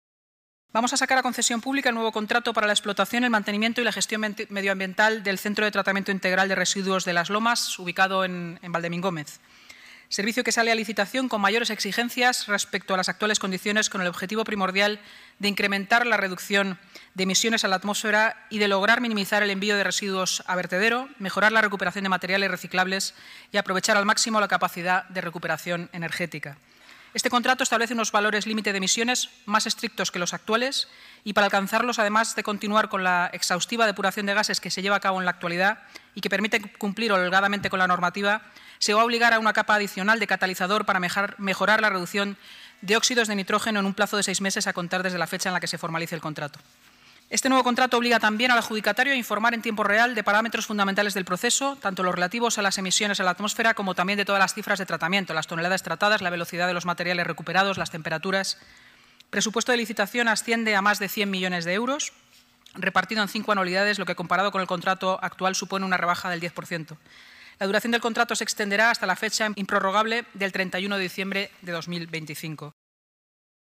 Nueva ventana:Inmaculada Sanz, portavoz Gobierno municipal